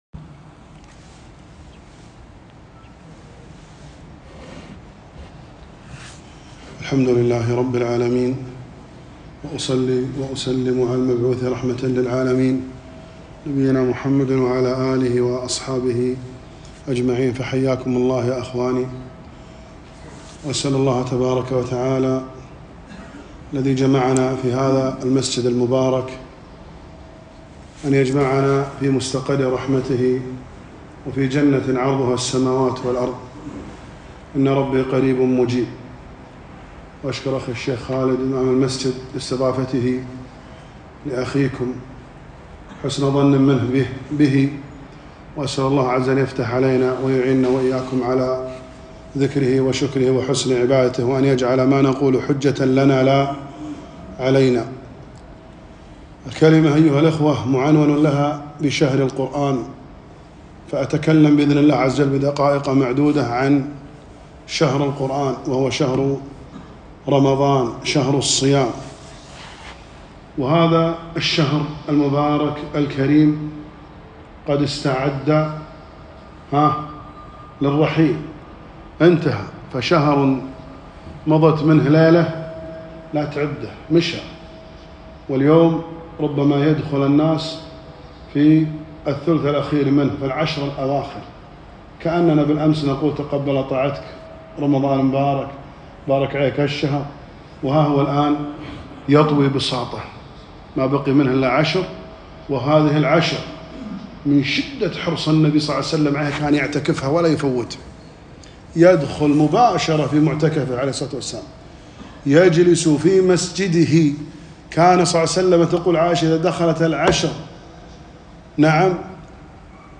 كلمة - شهر القرآن .. شارف على الرحيل